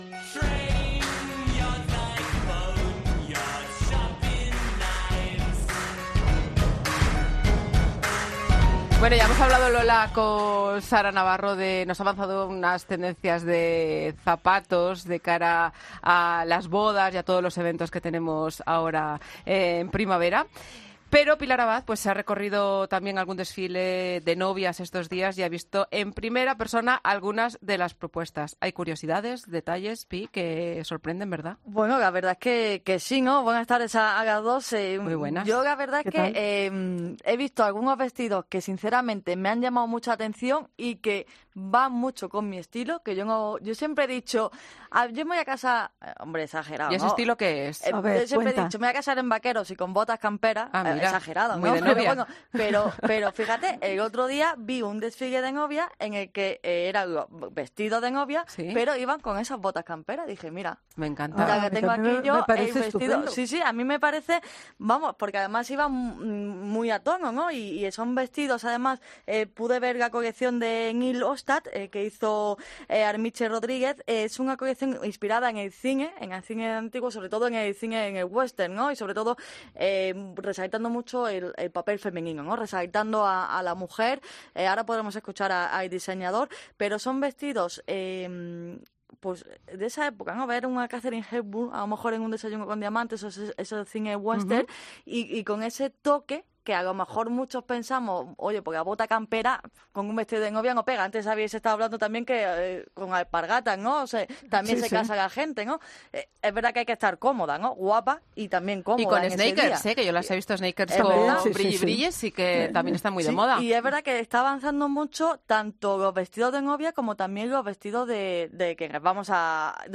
Esta entrevista forma parte de la séptima entrega del podcast COPE Cool, con lo último en belleza, moda y decoración